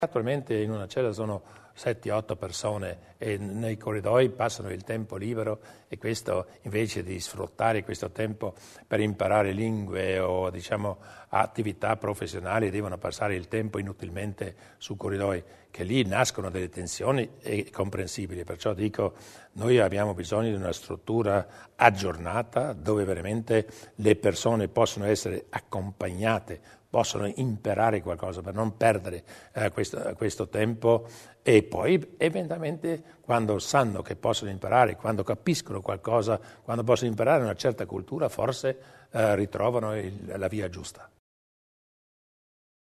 Il Presidente Durnwalder sul progetto del nuovo carcere